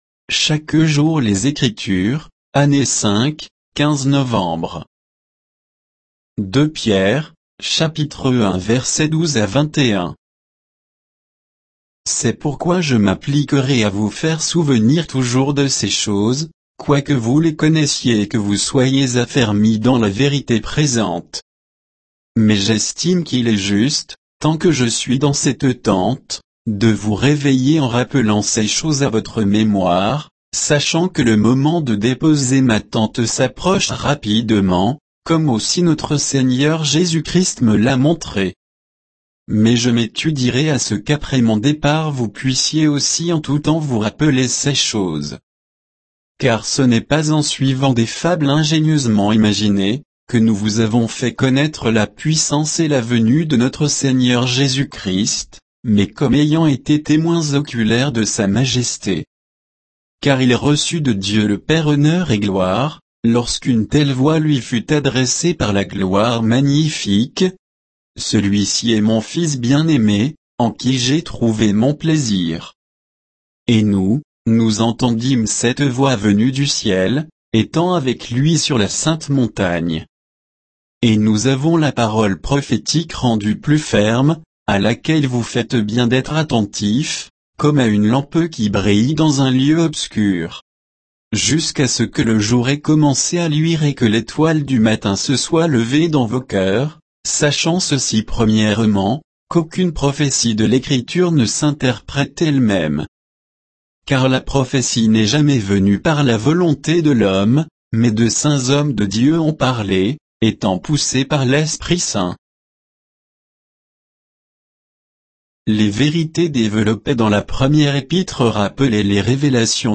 Méditation quoditienne de Chaque jour les Écritures sur 2 Pierre 1, 12 à 21